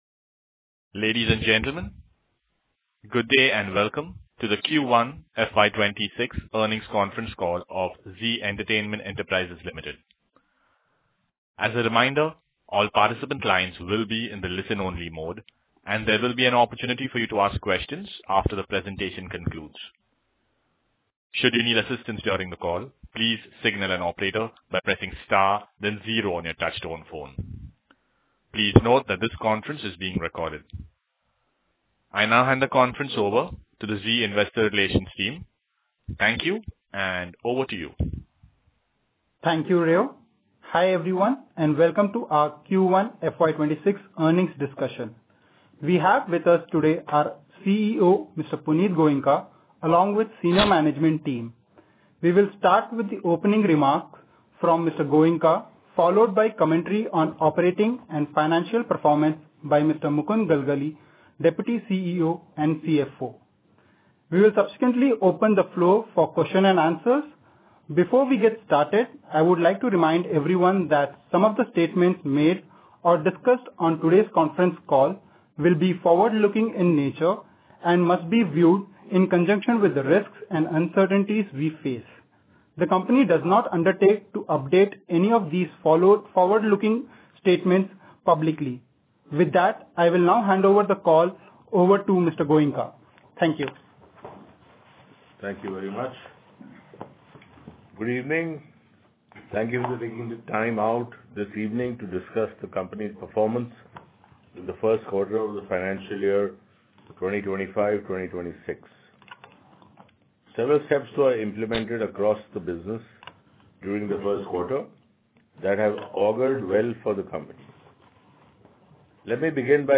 Concalls
Q1_FY26_Earnings_Call_Audio_Recording.mp3